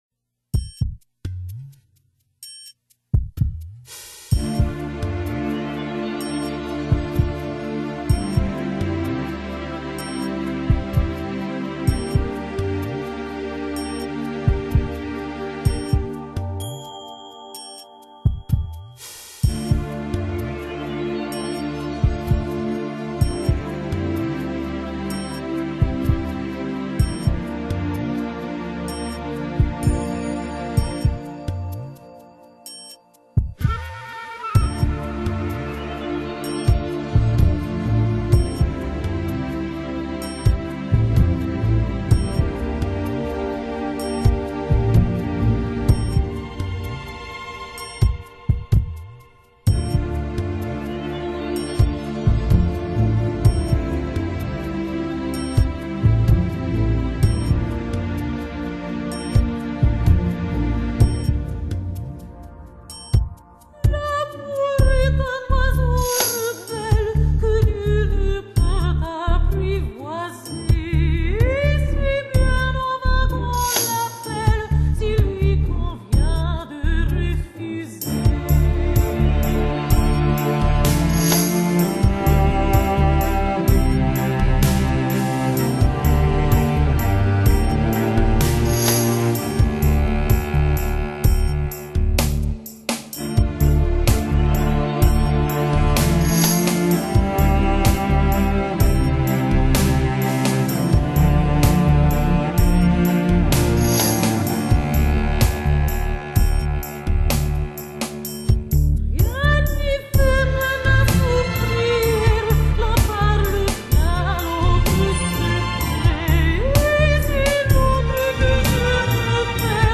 充满现代气息的氛围舞曲